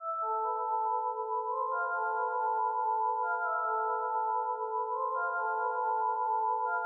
软陷阱钟
描述：ap铃声
Tag: 140 bpm Trap Loops Bells Loops 1.15 MB wav Key : Unknown